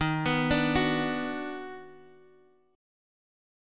D#mM7#5 Chord